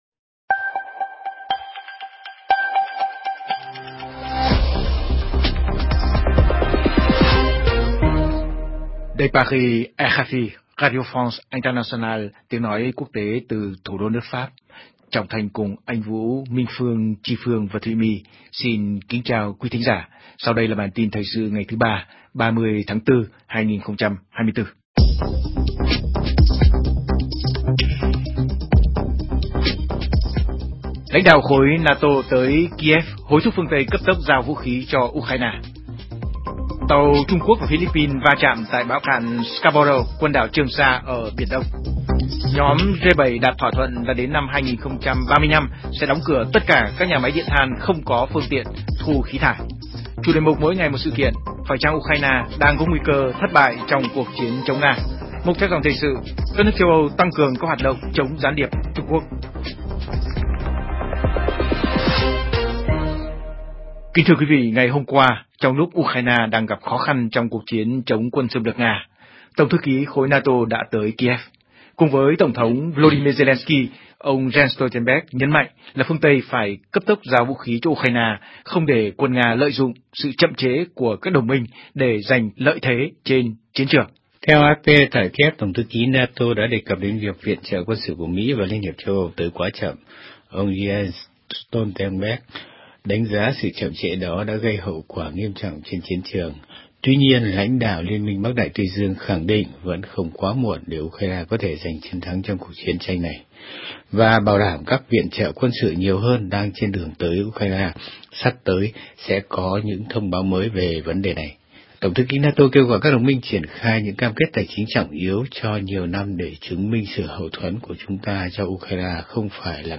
CHƯƠNG TRÌNH PHÁT THANH 60 PHÚT Xem tin trên website RFI Tiếng Việt Hoặc bấm vào đây để xem qua Facebook